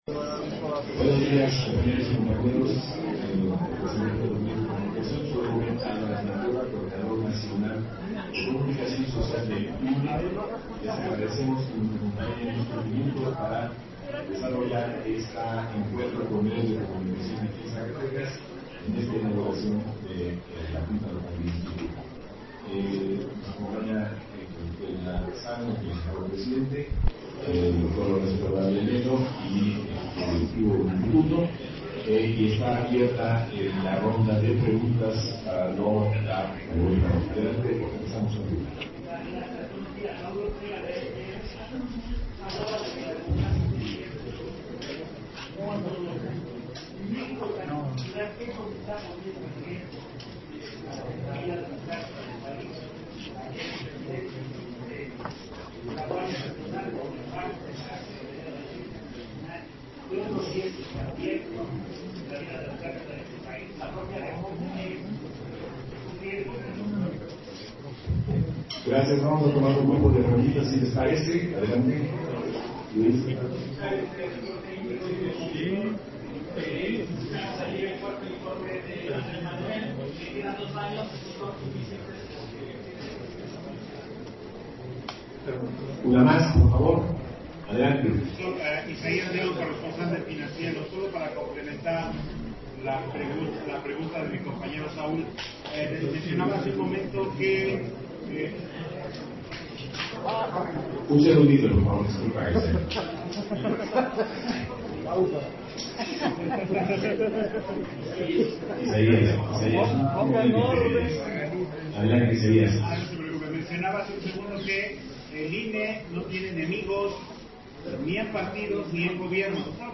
Conferencia de prensa que ofreció Lorenzo Córdova, durante su visita a la Junta Local Ejecutiva de Zacatecas